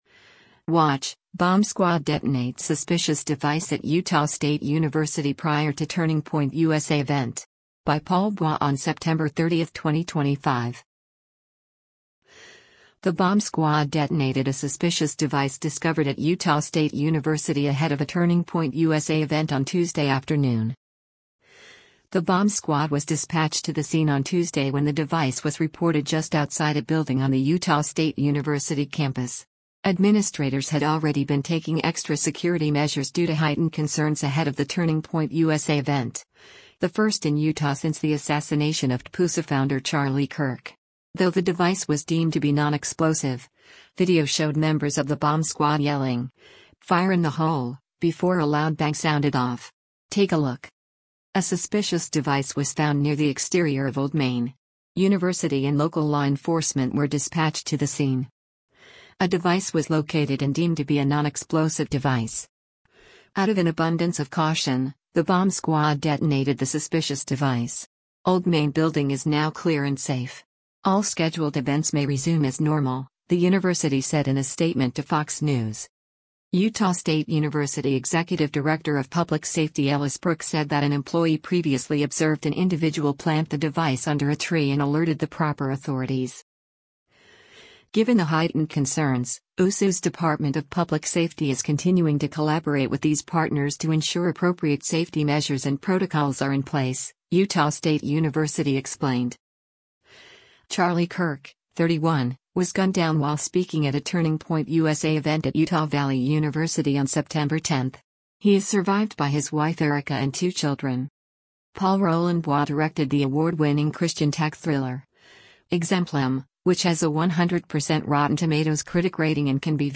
Though the device was deemed to be “non-explosive,” video showed members of the bomb squad yelling, “fire in the hole,” before a loud bang sounded off.